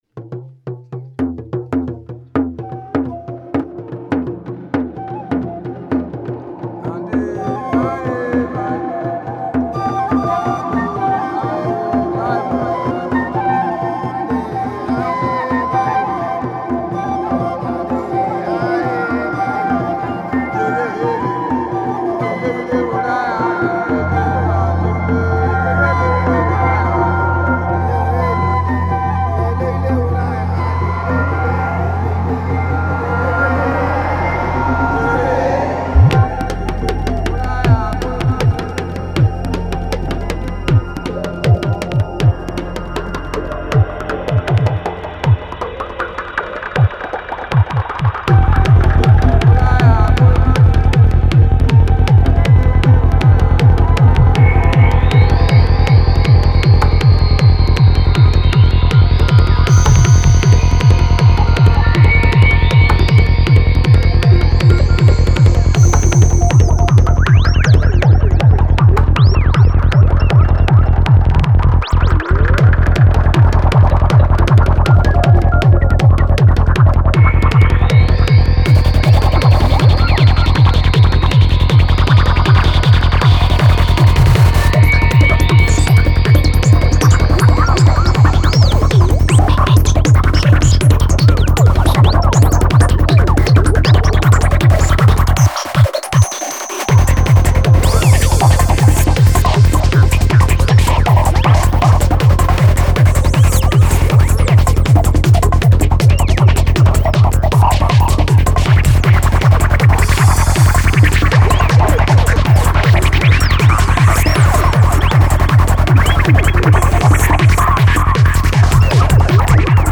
Назад в Dark, Forest Psytrance
Style: Dark Psytrance